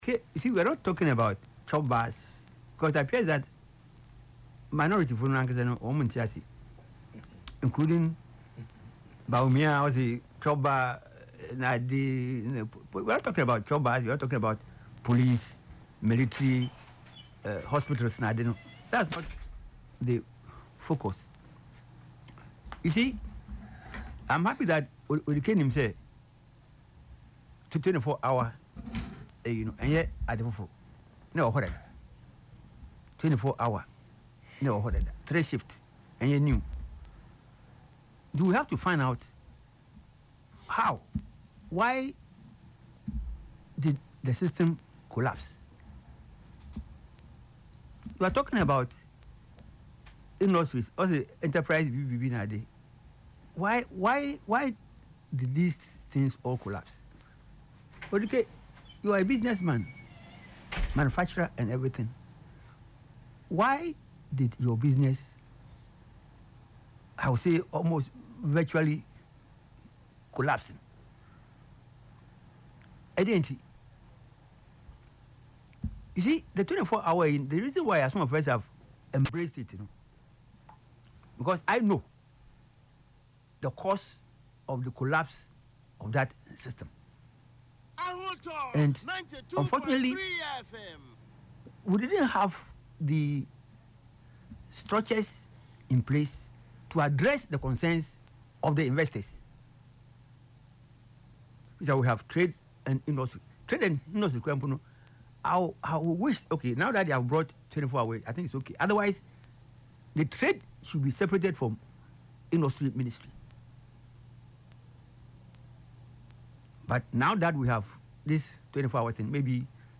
Speaking on Ahotor FM’s Yepe Ahunu programme on Saturday, February 7,